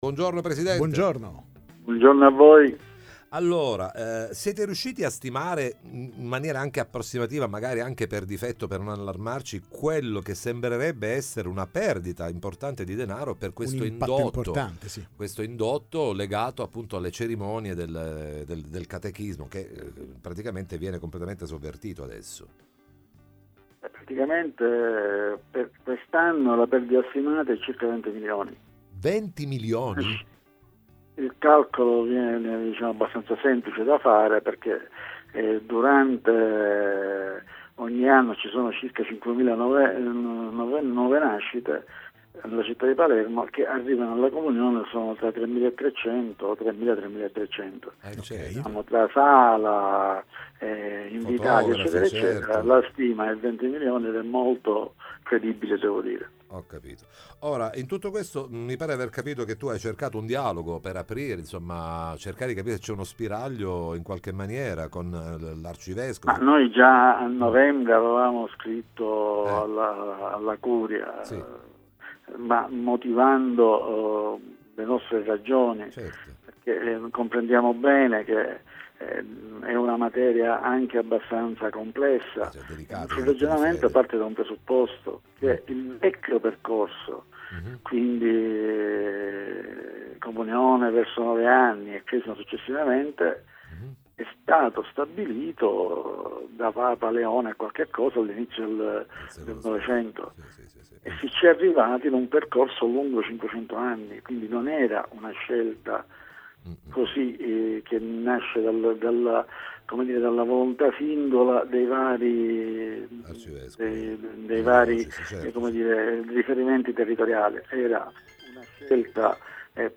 Una perdita per l’indotto di circa 20Mln di euro Interviste Time Magazine 16/03/2026 12:00:00 AM